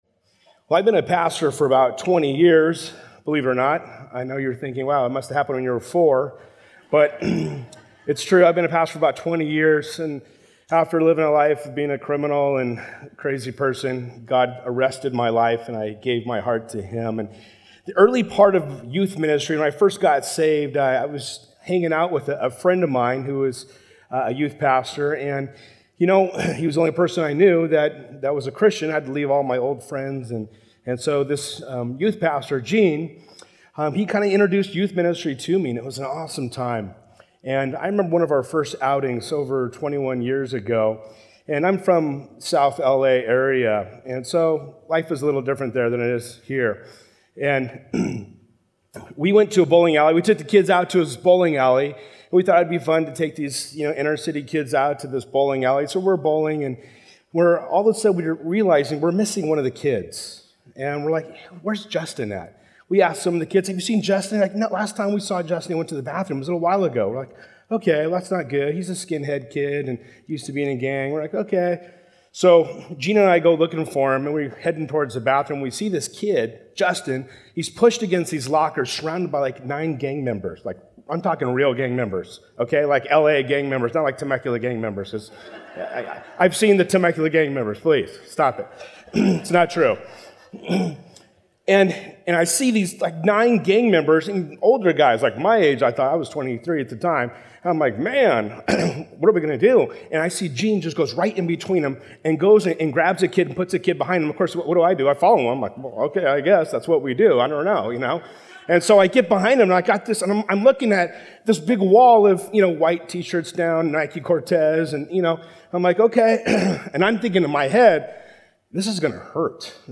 A verse-by-verse expository sermon through 2 Chronicles 20:1